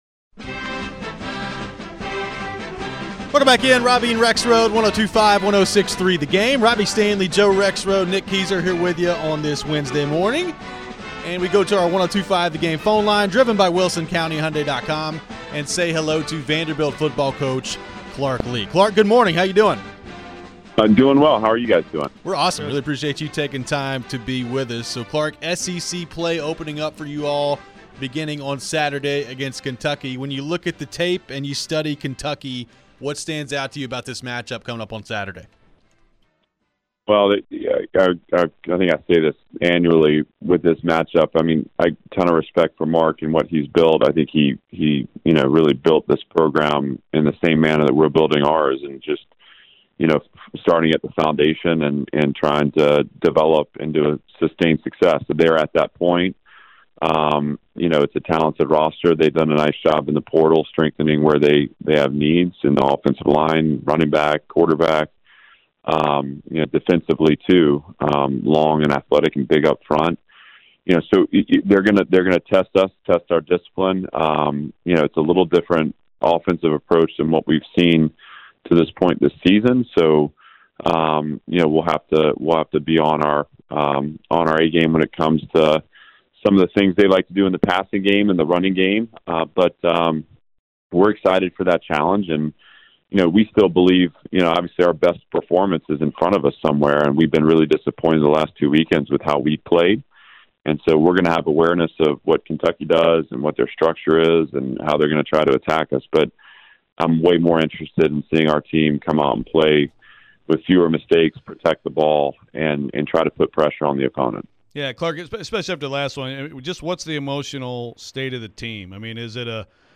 Clark Lea Interview (9-20-23)
Vanderbilt football head coach Clark joined for his weekly visit after a loss at UNLV. How is Clark feeling at this early point in the season?